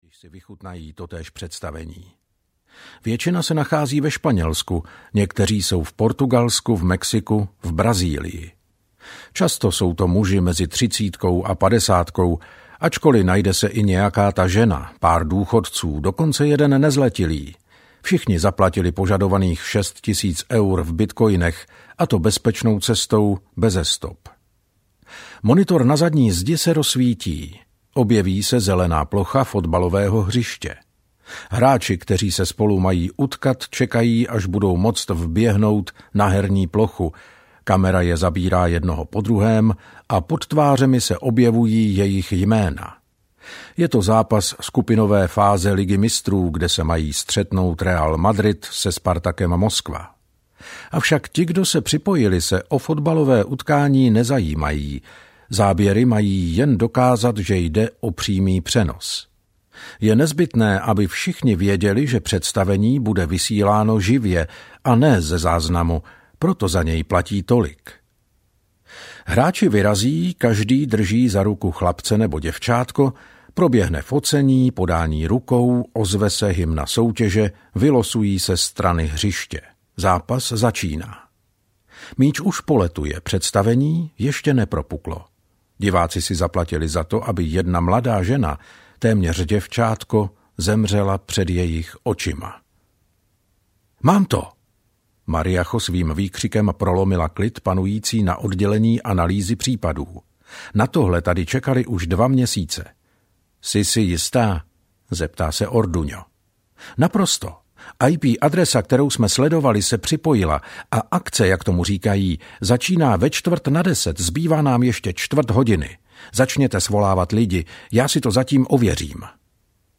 Purpurová síť audiokniha
Ukázka z knihy